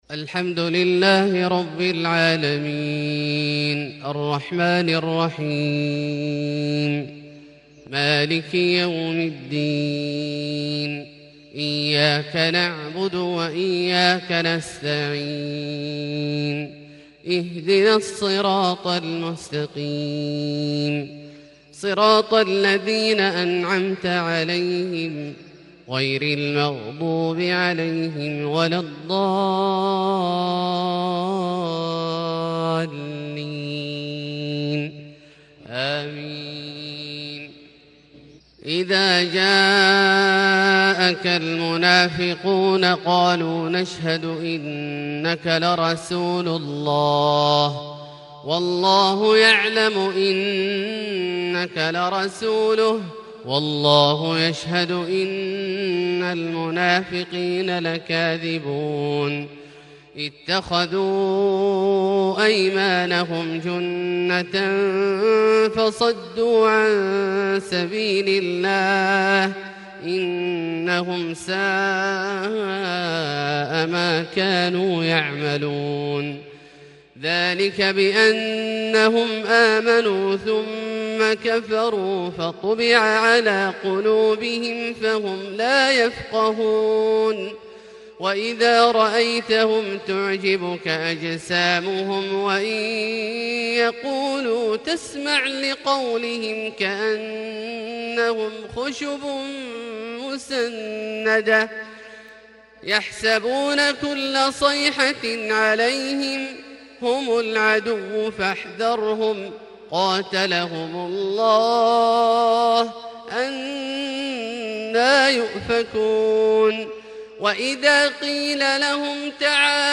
فجر 5-7-1441 هـ سورة المنافقون > ١٤٤١ هـ > الفروض - تلاوات عبدالله الجهني